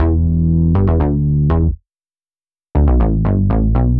Index of /musicradar/french-house-chillout-samples/120bpm/Instruments
FHC_SulsaBass_120-E.wav